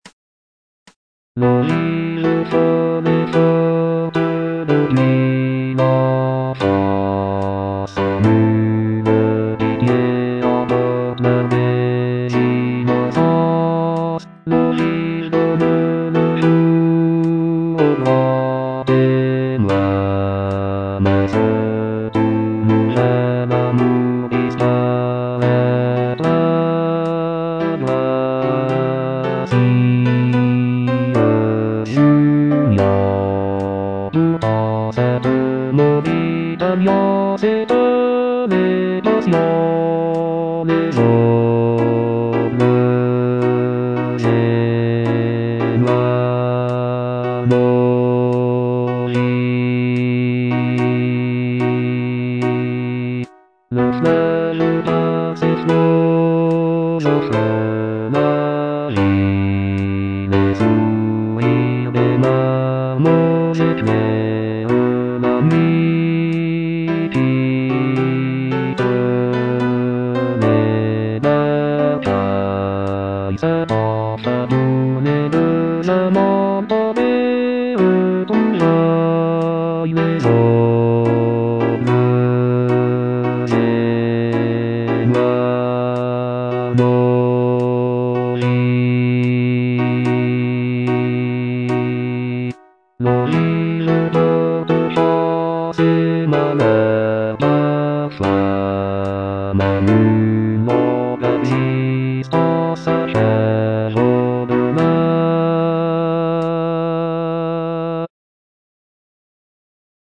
Répétition SATB par voix
Basse